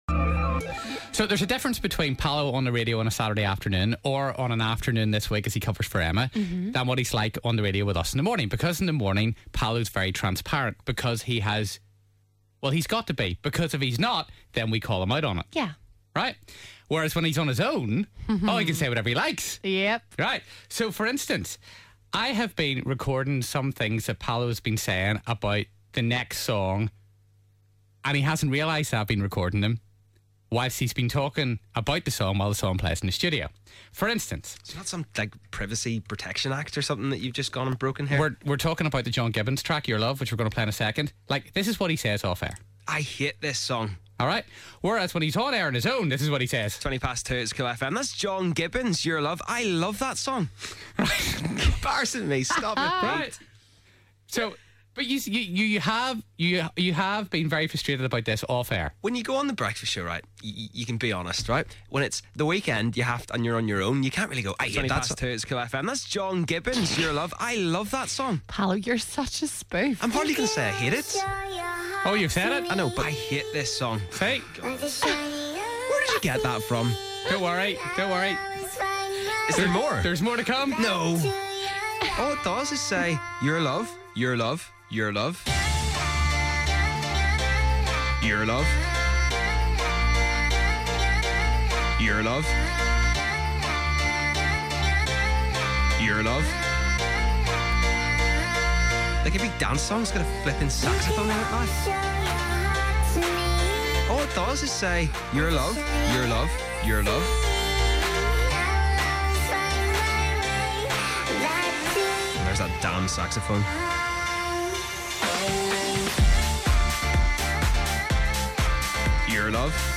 So we sneakingly made a remix of his TRUE thoughts off-air.